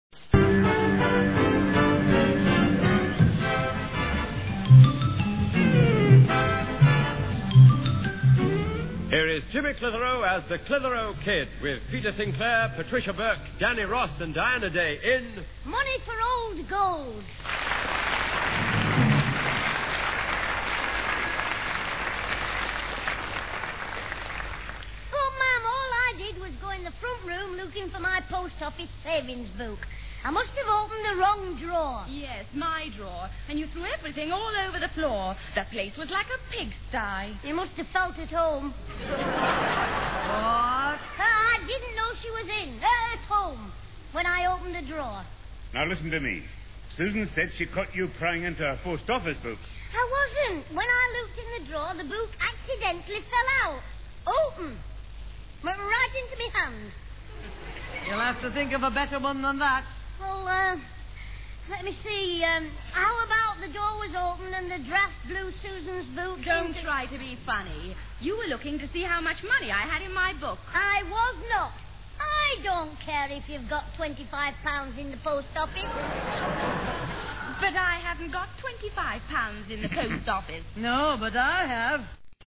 He played the naughty schoolboy for a total of 17 series.